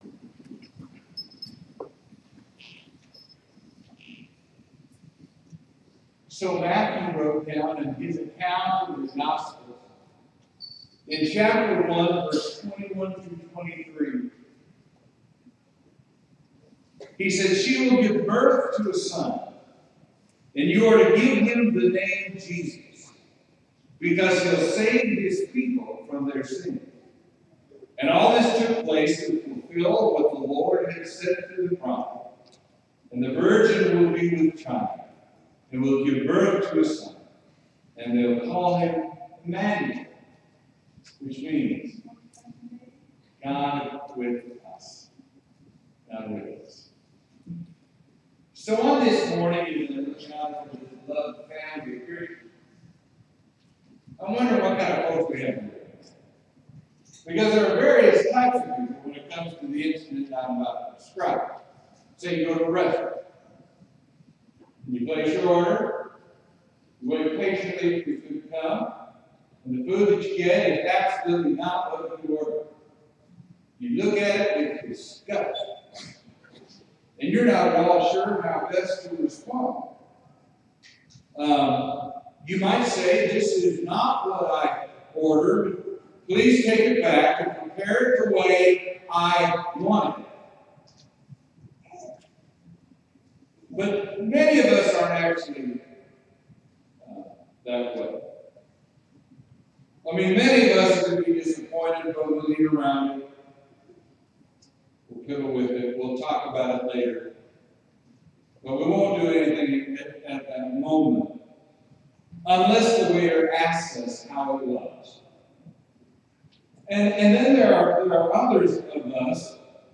Sermon: What if Jesus were never born?